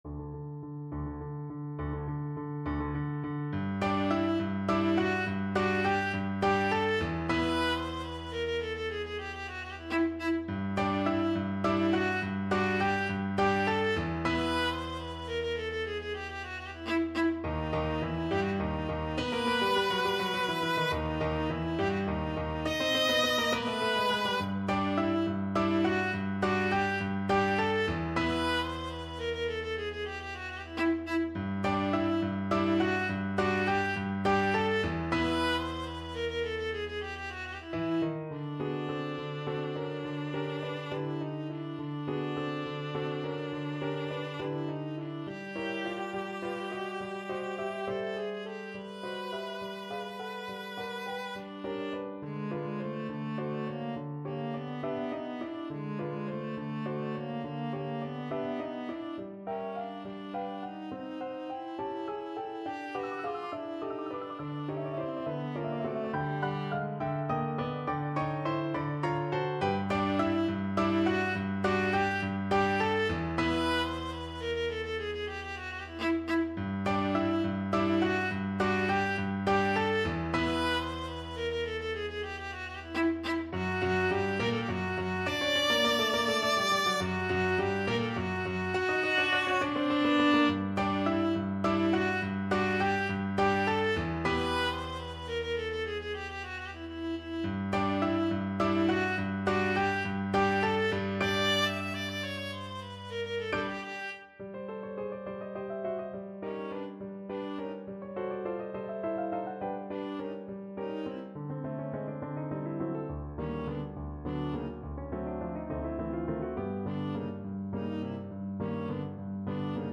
Viola
G major (Sounding Pitch) (View more G major Music for Viola )
Allegro .=69 .=69 (View more music marked Allegro)
3/4 (View more 3/4 Music)
G4-E6
Classical (View more Classical Viola Music)